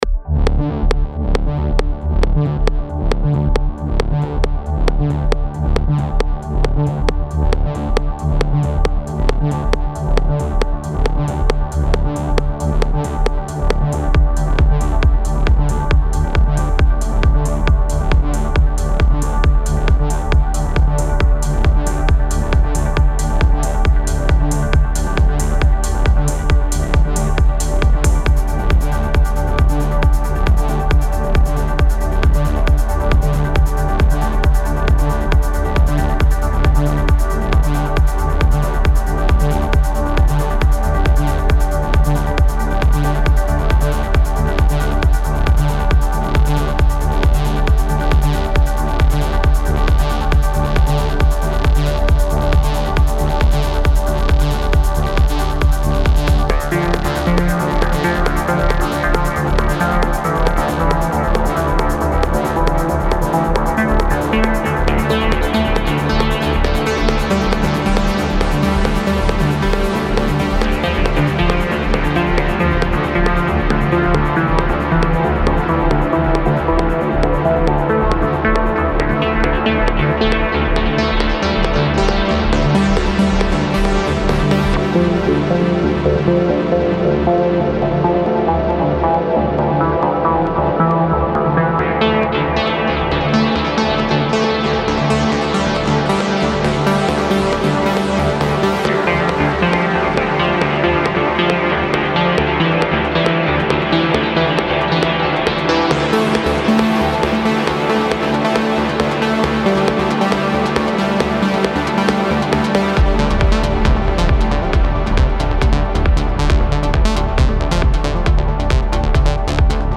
Safe me life (Original pre mix) Progressive Trance
На днях нашёл кое что из старых запасов, немного подсвёл и решил что дам и мультитрек этой композиции если будет желание посводить, покрутить,дописать и etc. С низом я промазал 100 процентов - так как не могу попасть как не крути, а уж тем более на мониторах в моей "комнате" Как думаете что сделать с серединой, да и вообще что думаете по всему ачх балансу композиции?